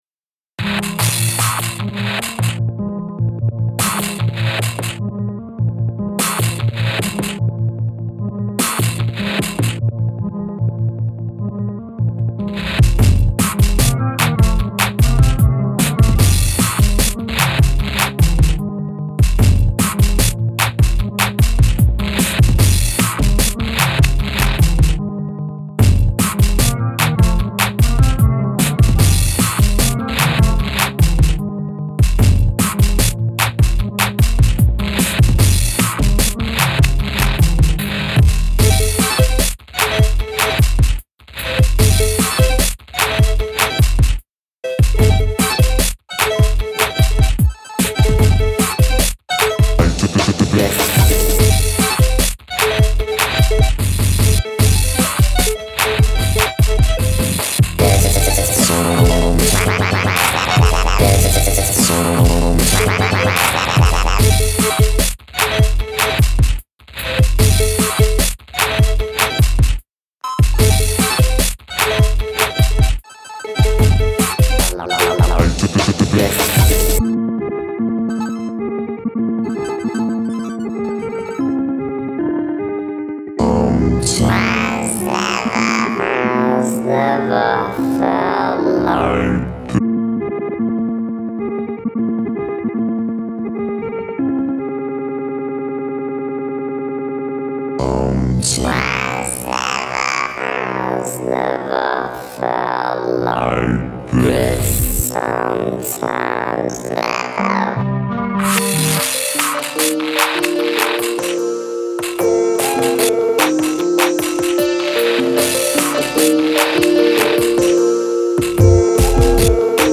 electro/synth territory